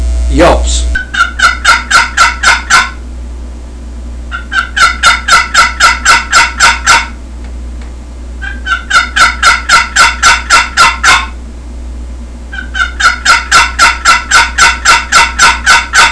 • Easy to use and perfect for anyone--excellent medium and high pitched hen yelps, cackles, and cutts with just the right amount of rasp.